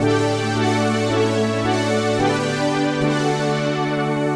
AM_VictorPad_110-A.wav